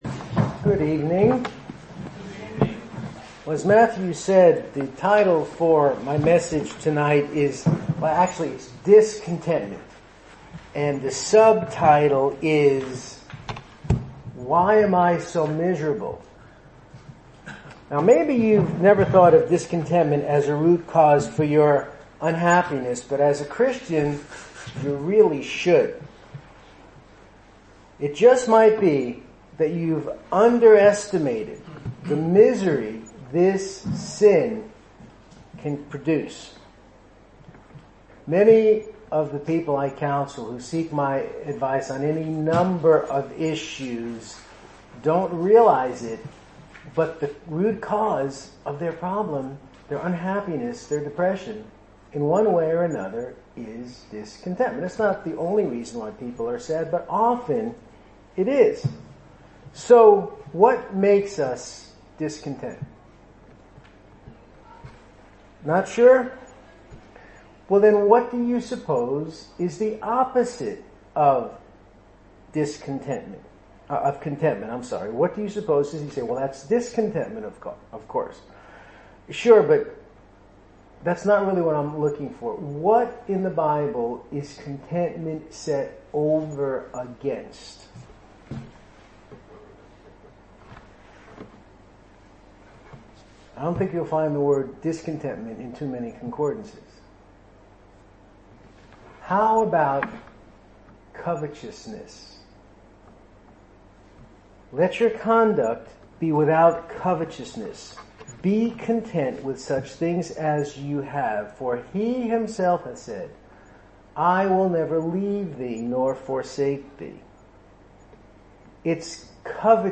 2015 Service Type: Sunday Evening Speaker